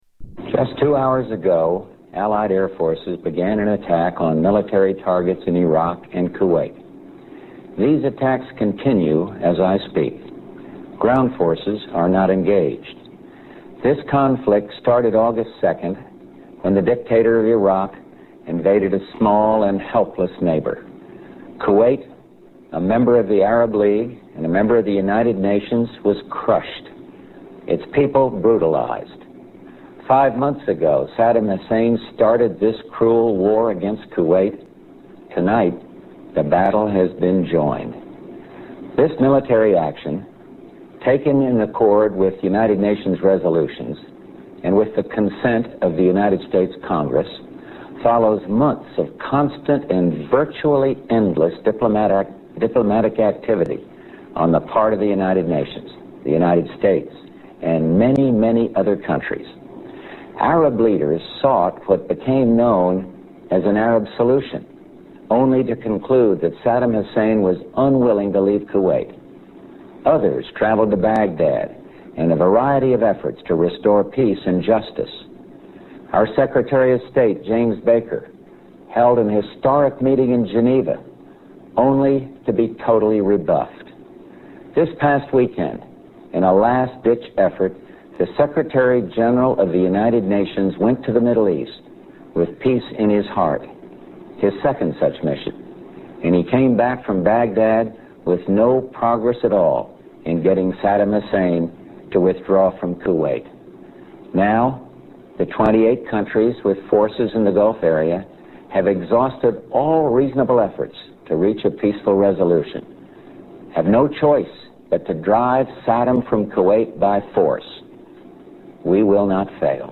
George HW Bush announces start of Persian Gulf war